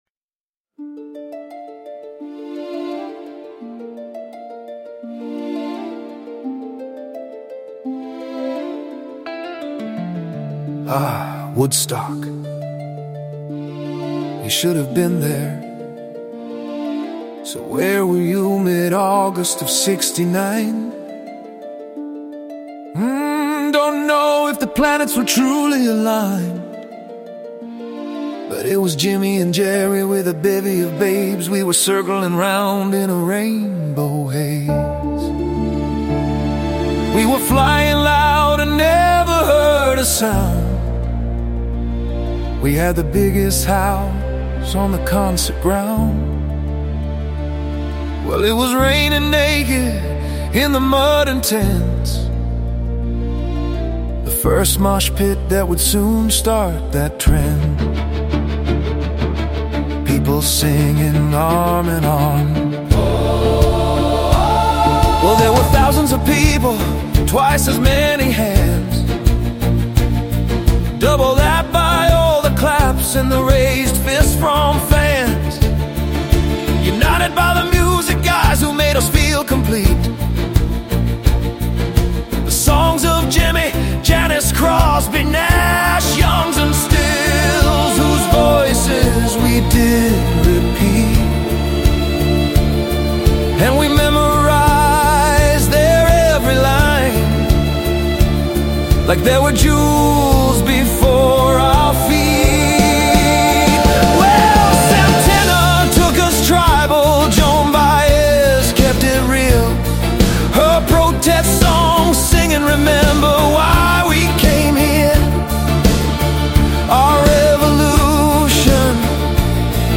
Then enter bass and drums after 8 bars
electric guitar, different male vocals